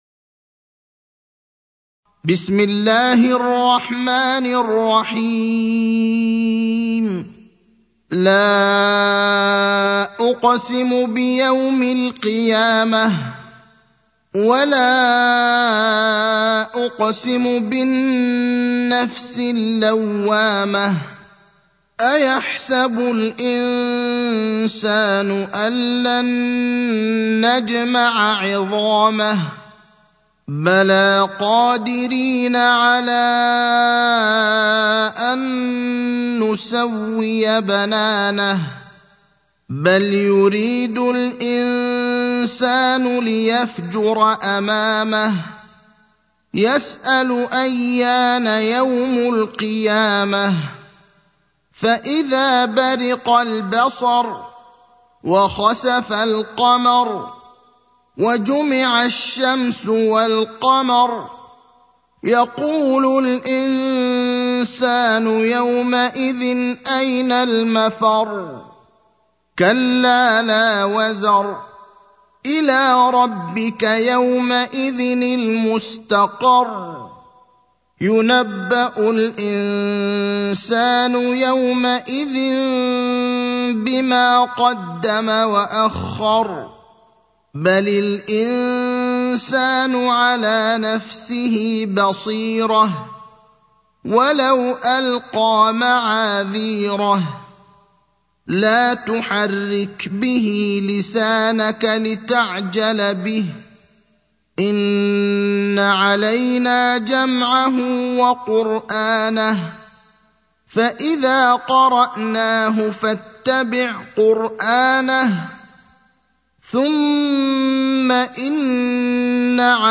المكان: المسجد النبوي الشيخ: فضيلة الشيخ إبراهيم الأخضر فضيلة الشيخ إبراهيم الأخضر القيامة (75)